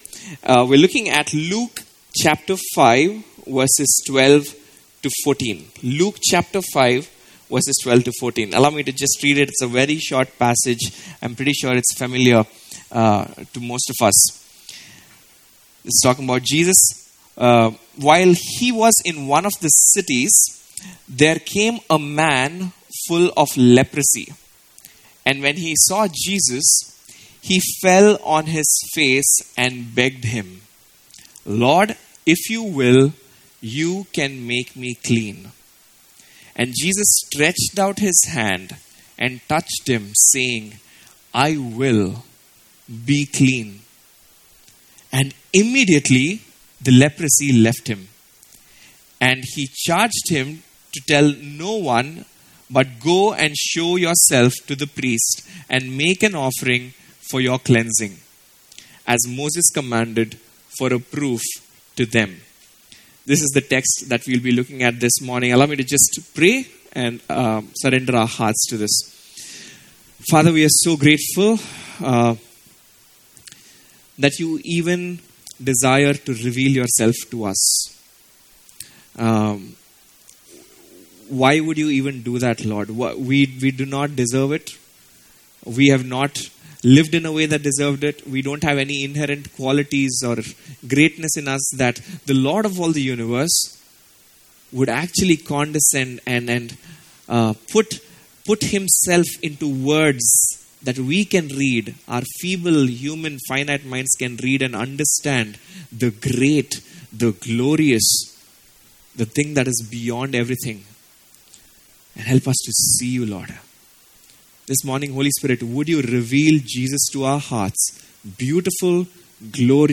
From Series: "Explore Sermons"